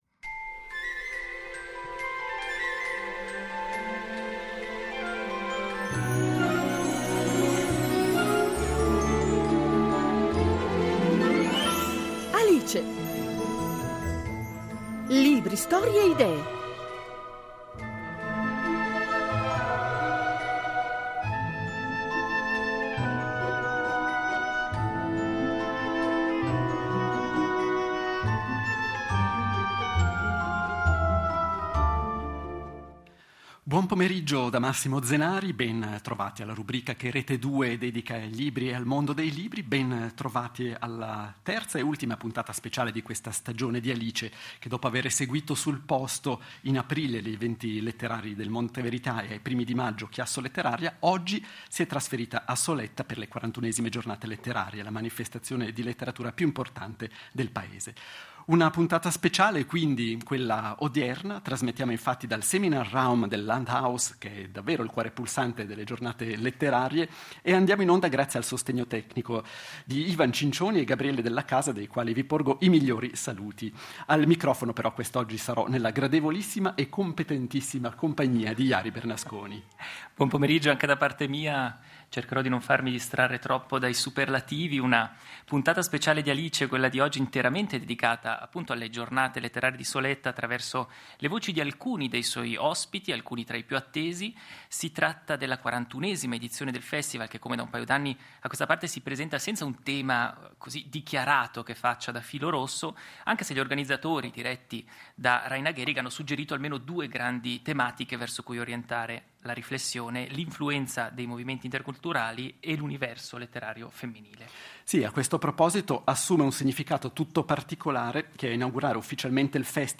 Così come aveva seguito sul posto gli Eventi letterari del Monte Verità e ChiassoLetteraria , “Alice” non mancherà neppure le 41esime Giornate letterarie di Soletta , la maggiore manifestazione nazionale dedicata alla letteratura svizzera (30 maggio-2 giugno).